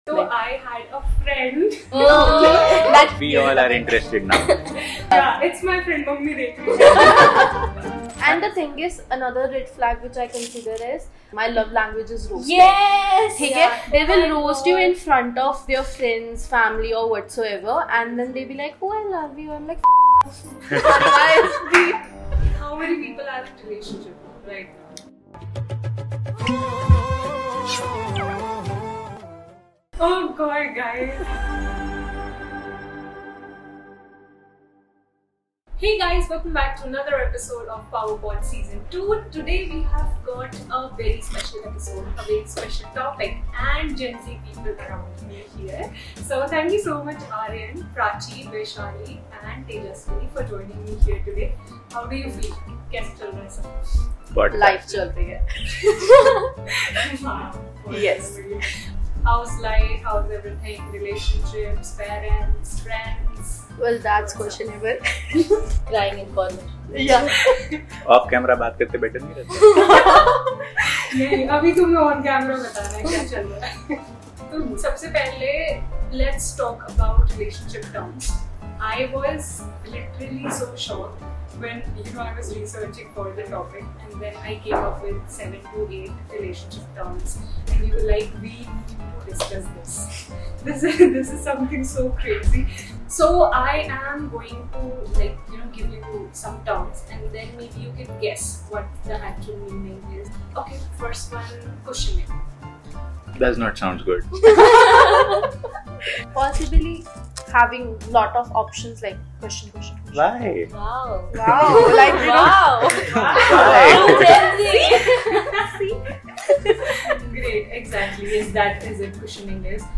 They discuss how terms like “benching” or “pocketing” are not just buzzwords but actually are reflections of the challenges of navigating commitment, communication, and self-worth in today’s digital generation. It is lighthearted, but at the same time, it is eye-opening. Red flags take the center stage in the discussion, with the group exploring behaviour patterns like late-night texting, constant “roasting” disguised as affection, and partners who hide relationships under the radar.